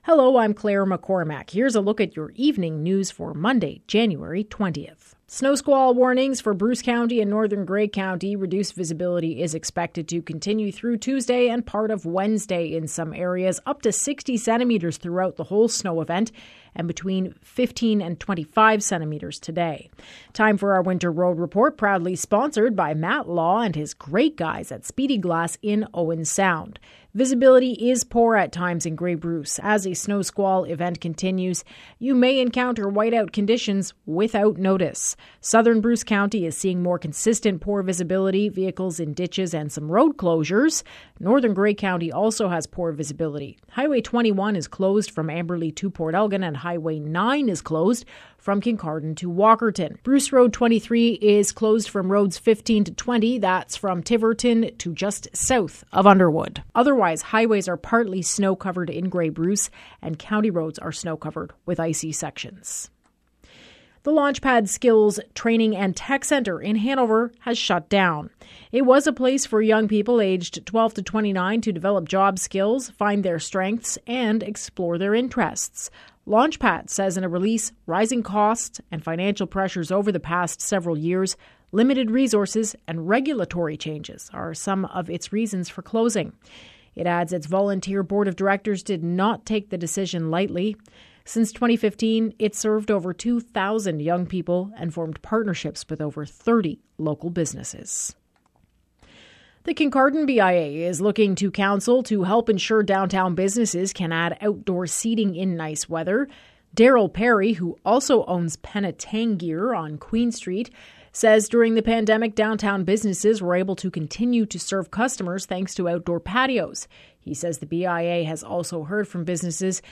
Evening News – Monday, January 20